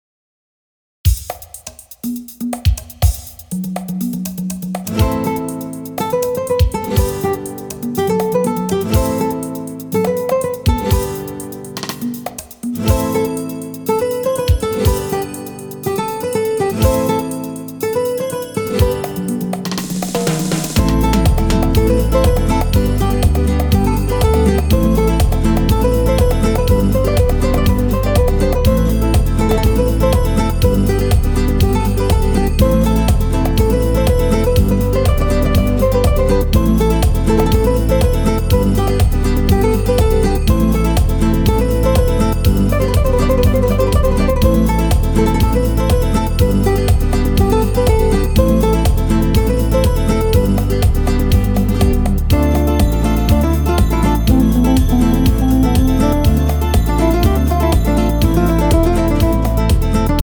Demo with guitar midi solo